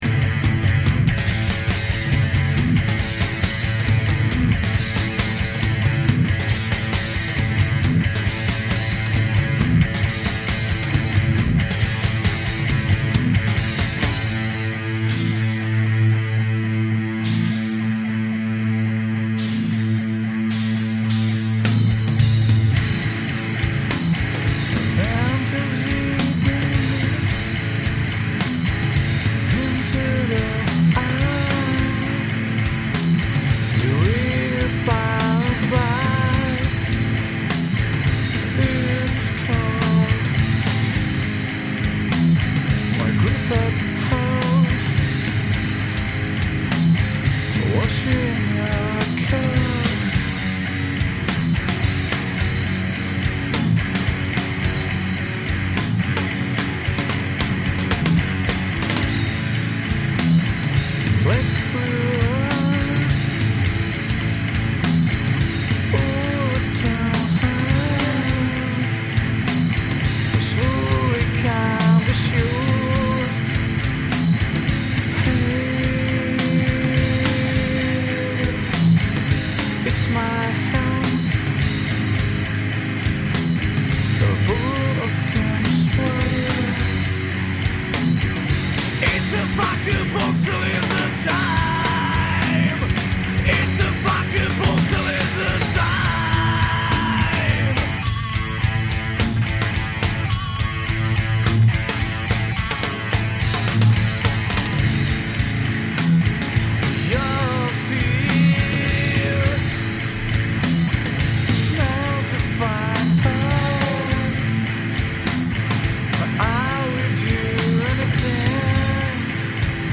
zpěv, kytara
bicí
basová kytara